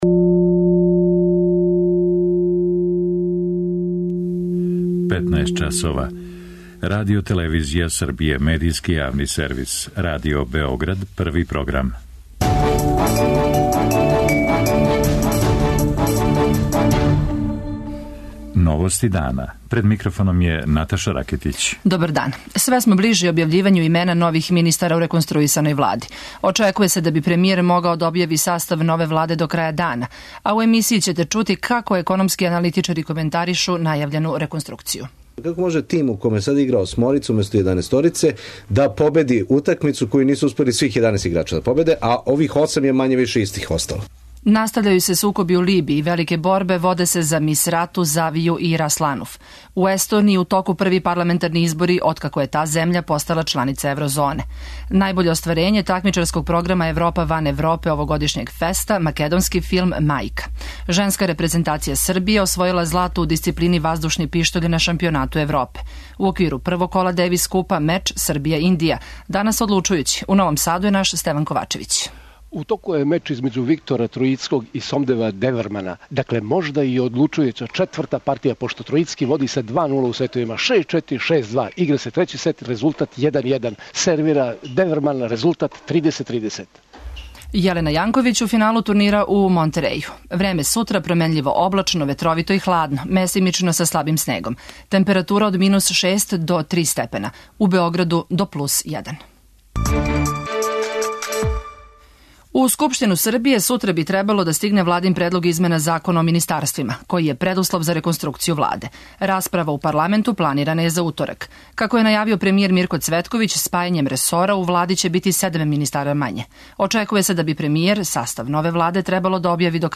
У емисији ћете чути како економски аналитичари коментаришу најављену реконструкцију владе. У Либији су све жешћи сукоби а вести које стижу су противречне.
Наши репортери извештавају са ФЕСТ-а, као и из Новог Сада где се игра Дејвис Куп меч Србија - Индија.